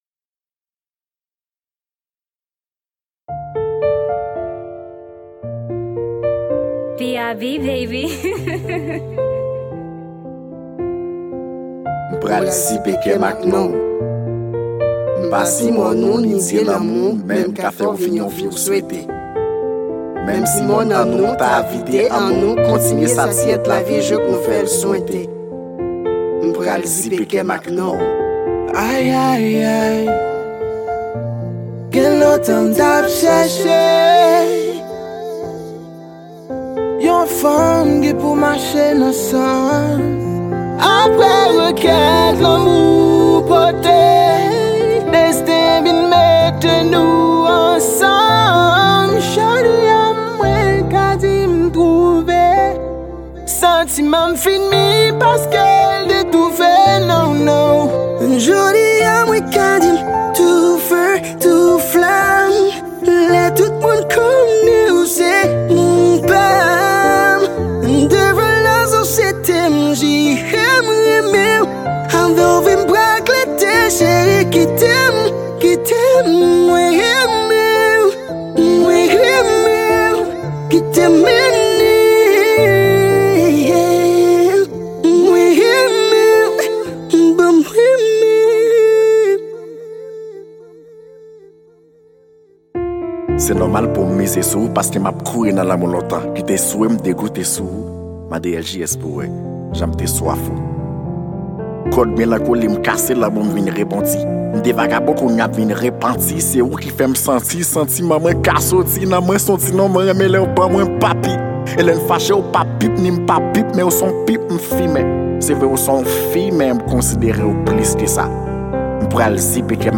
Genre : Slam